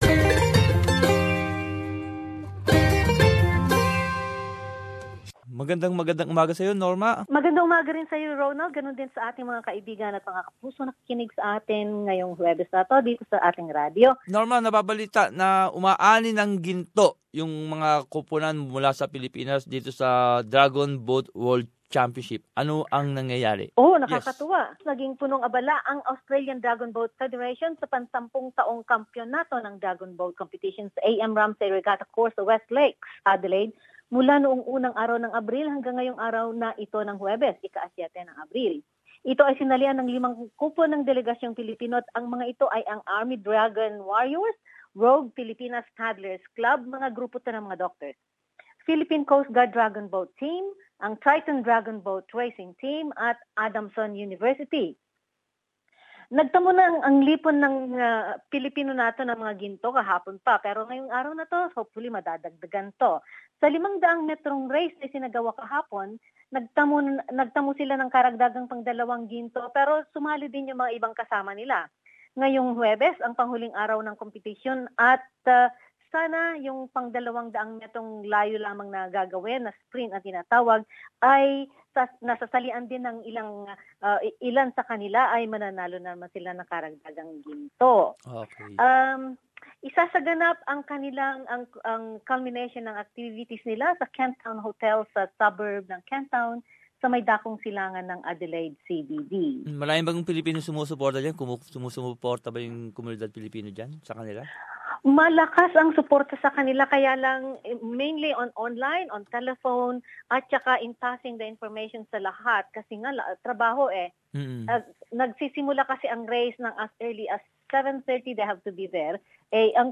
South Australia News.